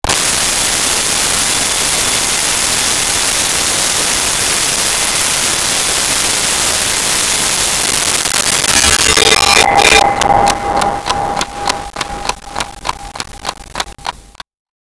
Alarm Clock 1
alarm-clock-1.wav